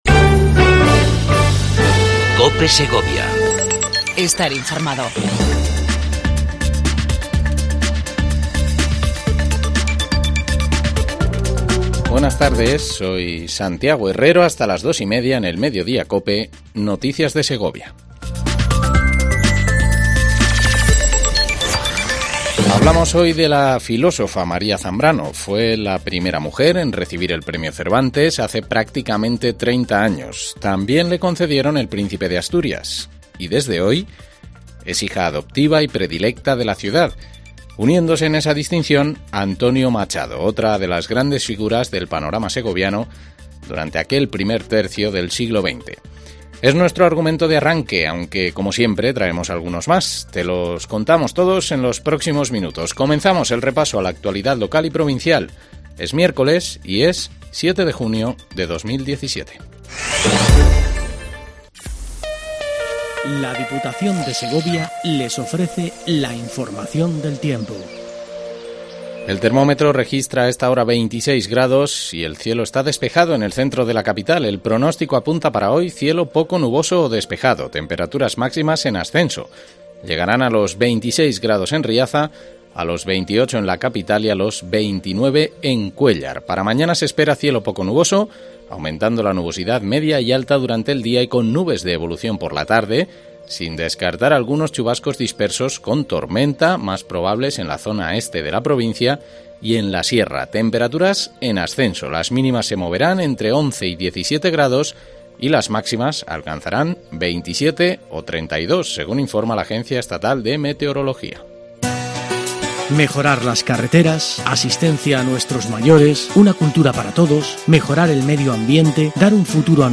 INFORMATIVO MEDIDOIA COPE EN SEGOVIA 07 06 17